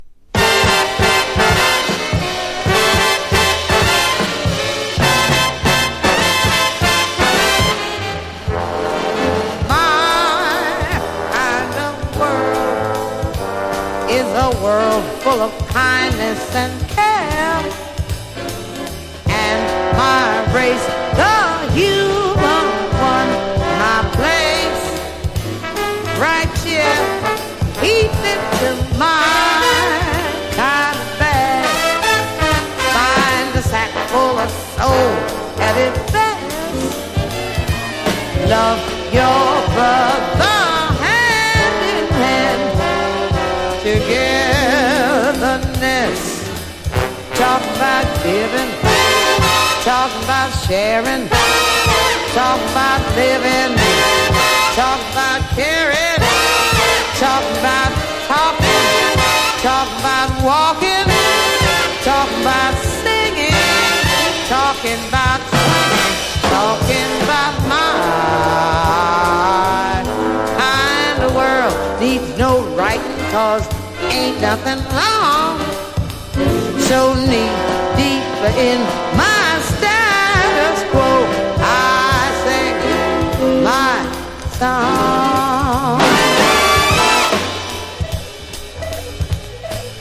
（1976年国内見本盤）：JAZZ